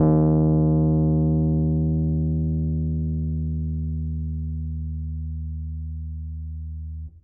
piano-sounds-dev
Rhodes_MK1